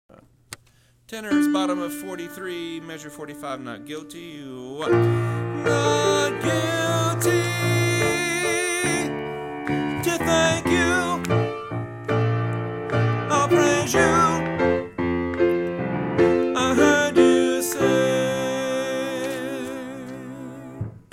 Not Guilty individual voice parts